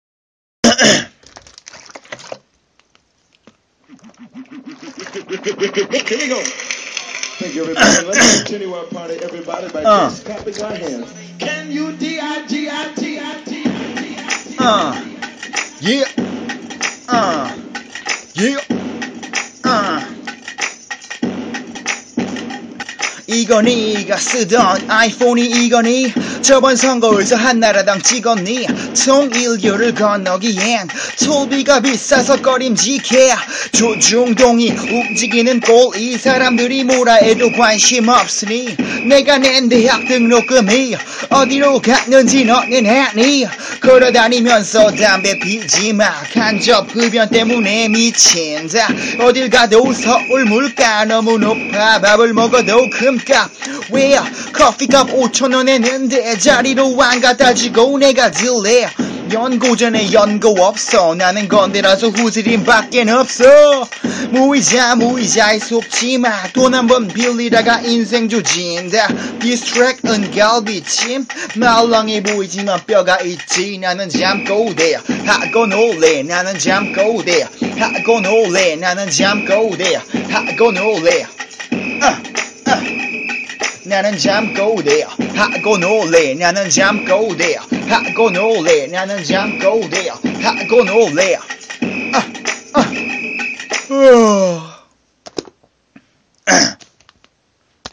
장비가 없어서 스마트폰으로 한번에 녹음했네요 ..